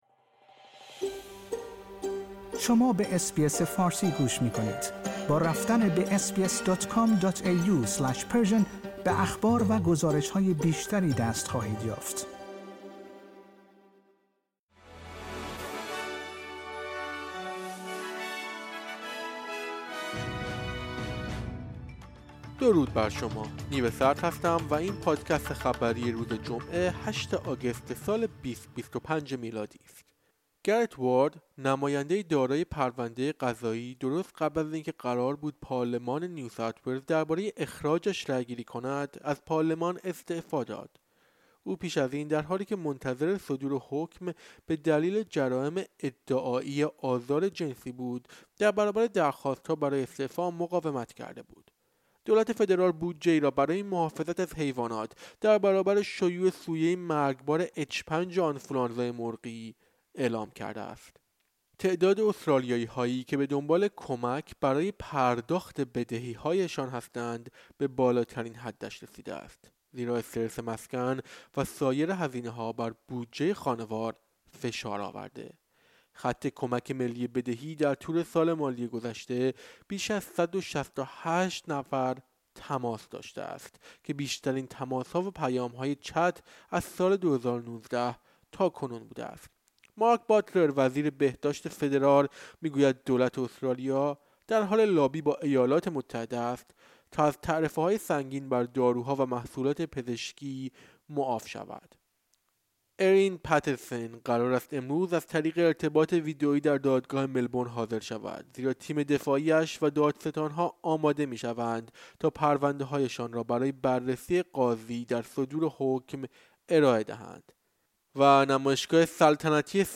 در این پادکست خبری مهمترین اخبار امروز جمعه ۸ آگوست ارائه شده است.